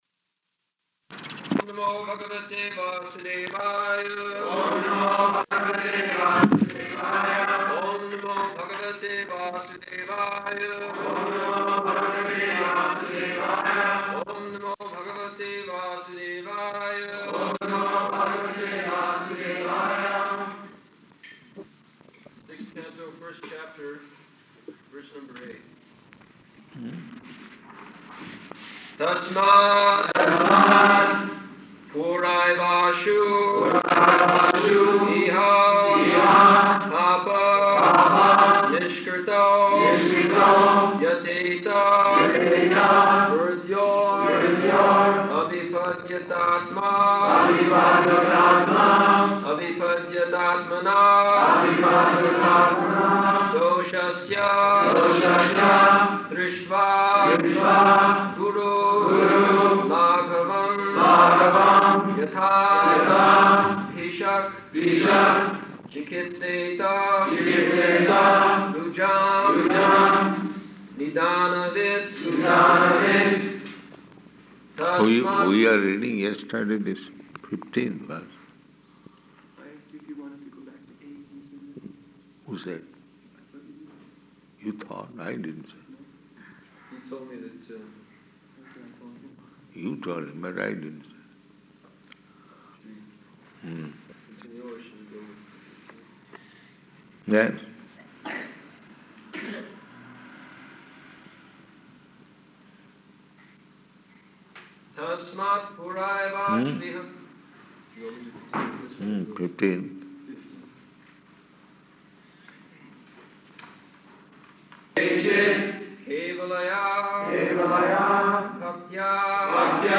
June 28th 1975 Location: Denver Audio file